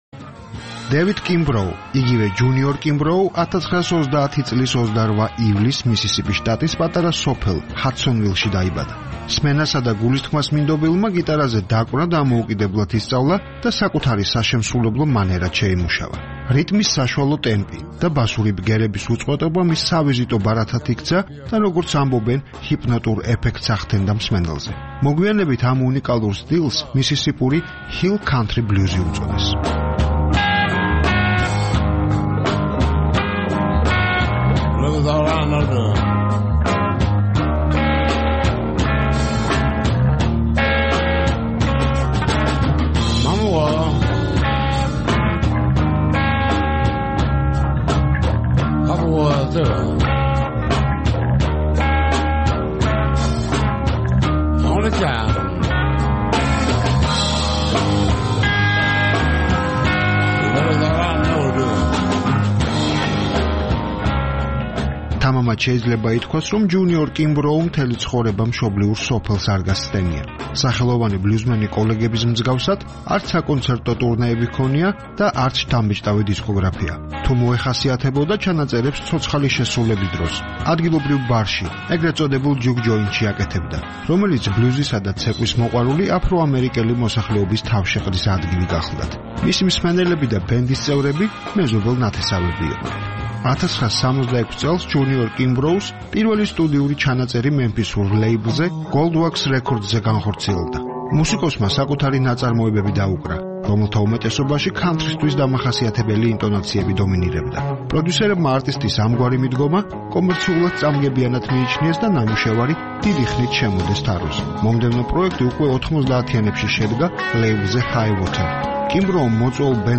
მუსიკა, მუსიკა
ჰოდა, როგორც ამგვარ მისისიპურ ყოფას სჩვევია, ბლუზით იხალისებდა ცხოვრებას. სმენასა და გულისთქმას მინდობილმა, გიტარაზე დაკვრა დამოუკიდებლად ისწავლა და საკუთარი საშემსრულებლო მანერაც შეიმუშავა. რიტმის საშუალო ტემპი და ბასური ბგერების უწყვეტობა მის სავიზიტო ბარათად იქცა და, როგორც ამბობენ, ჰიპნოტურ ეფექტს ახდენდა მსმენელზე. მოგვიანებით ამ უნიკალურ სტილს "მისისიპური ჰილ-ქანთრი-ბლუზი" უწოდეს.